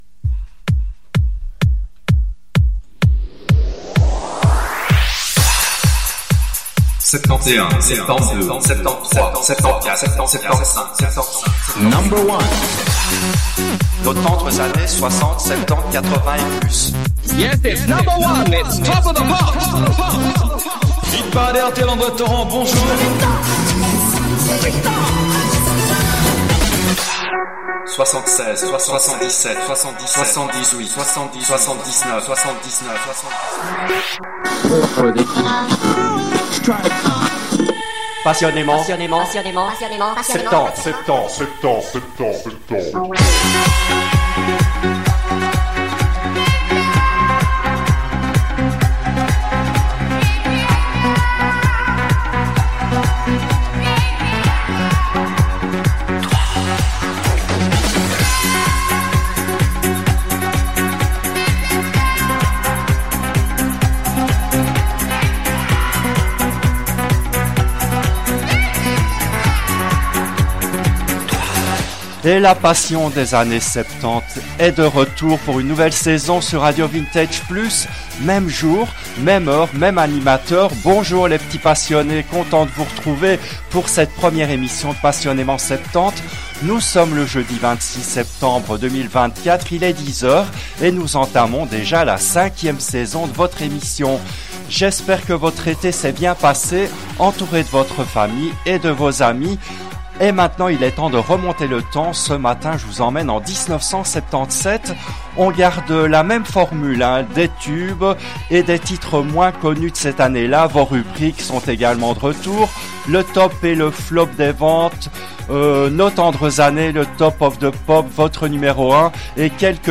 Il nous fait découvrir ou redécouvrir cette semaine les succès et les chansons oubliées de l’année 1977 dans son émission hebdomadaire qui a été diffusée en direct le jeudi 26 septembre 2024 à 10h depuis les studios belges de RADIO RV+.